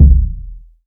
Kicks
KICK.60.NEPT.wav